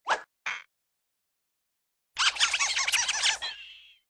AA_heal_tickle.ogg